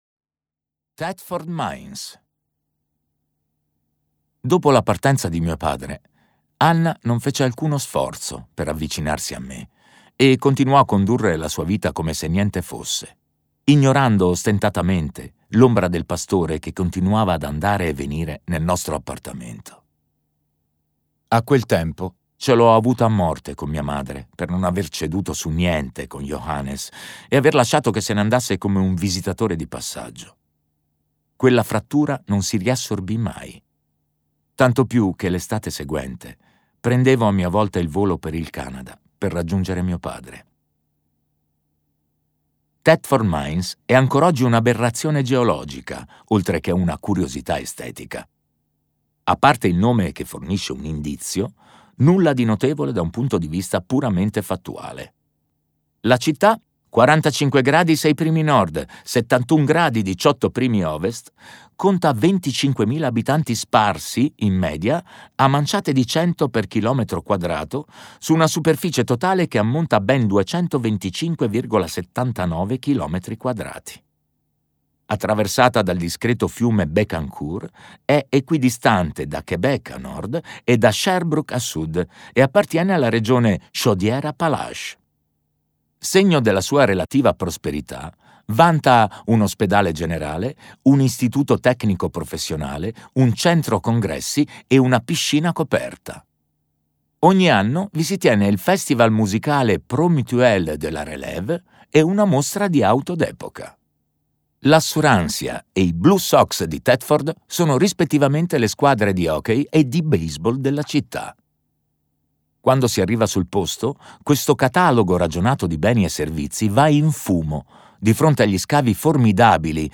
"Non stiamo tutti al mondo nello stesso modo" di Jean-Paul Dubois - Audiolibro digitale - AUDIOLIBRI LIQUIDI - Il Libraio